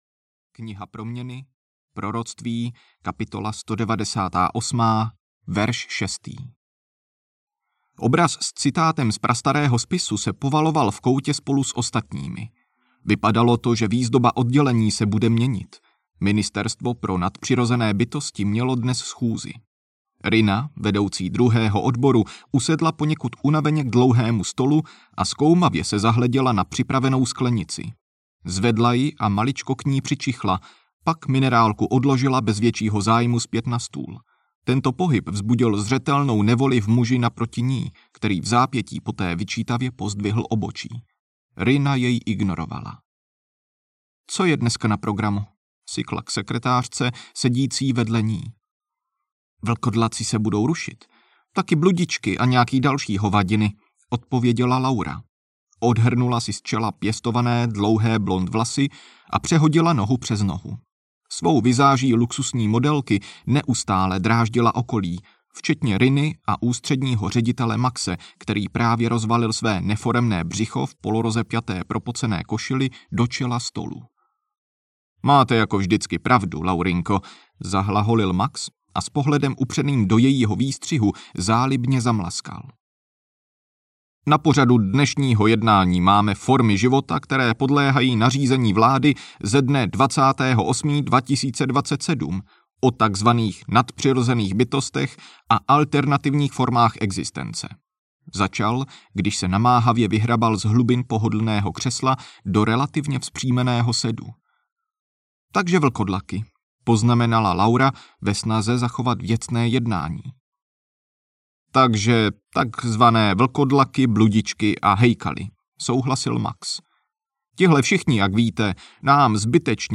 Návrat vlkodlaků audiokniha
Ukázka z knihy